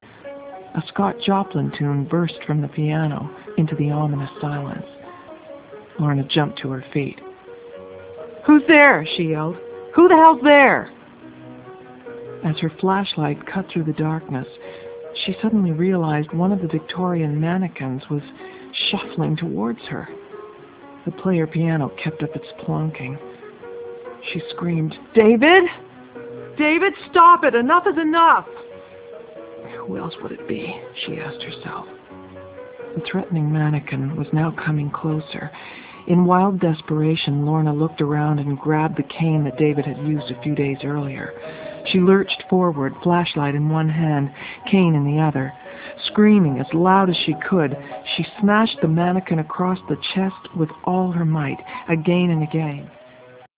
Talking Books